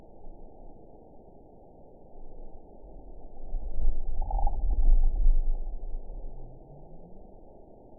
event 912358 date 03/25/22 time 12:02:21 GMT (3 years, 1 month ago) score 8.27 location TSS-AB03 detected by nrw target species NRW annotations +NRW Spectrogram: Frequency (kHz) vs. Time (s) audio not available .wav